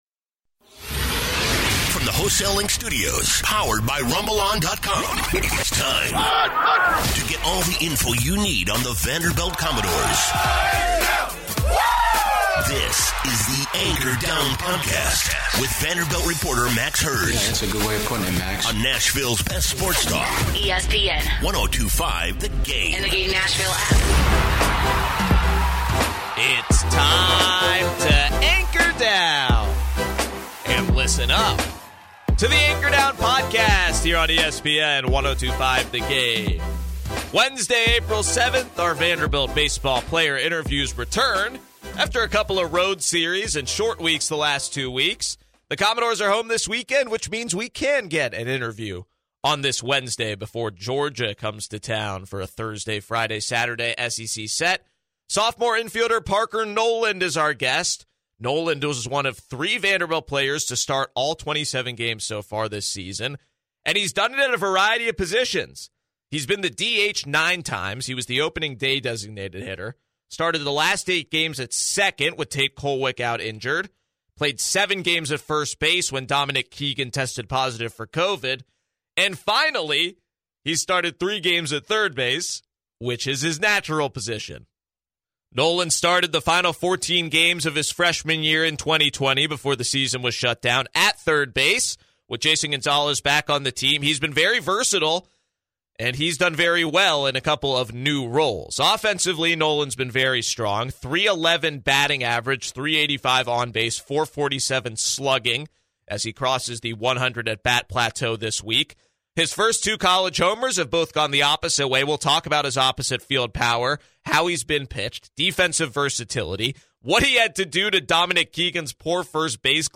player interview